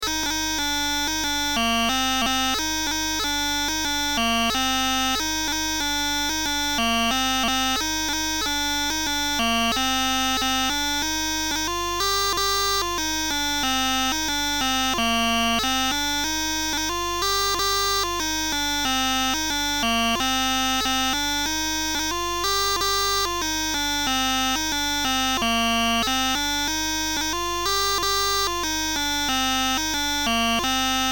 ( andro )